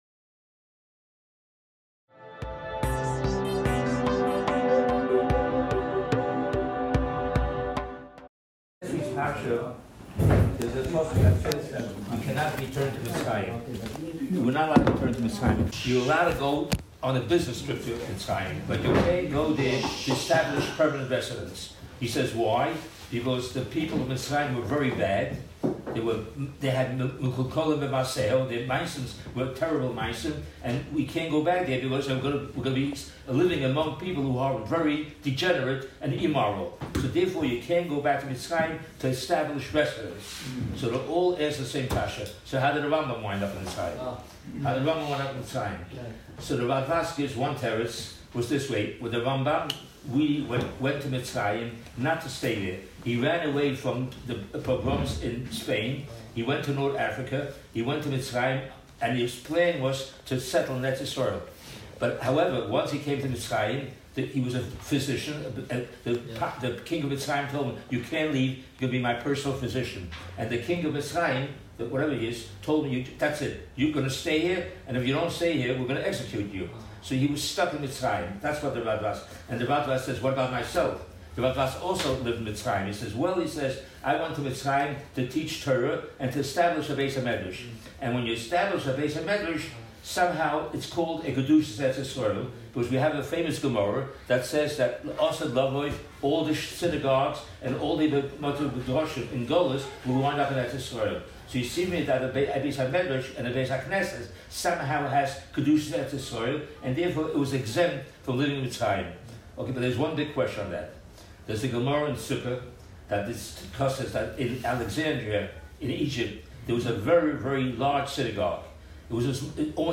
The prohibitions of “Living in Egypt.” The Rambam’s history. Guest shiur